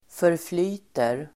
Uttal: [förfl'y:ter]